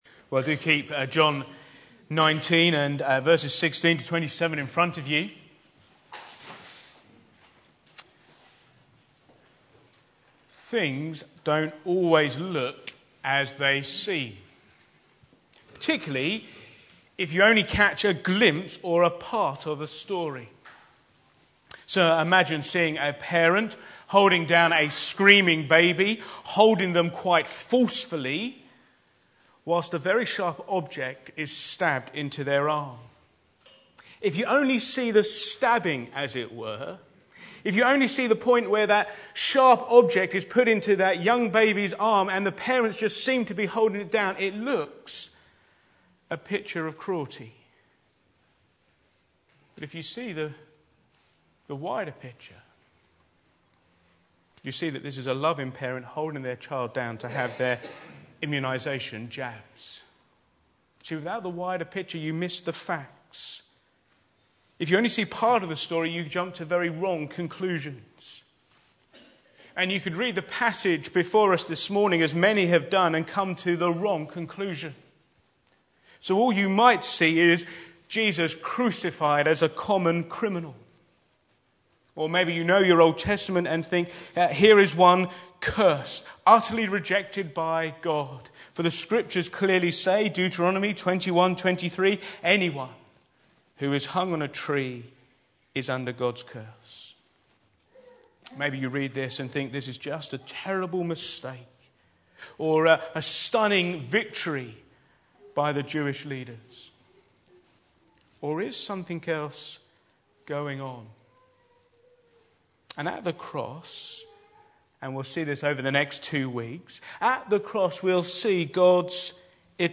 Back to Sermons Crucified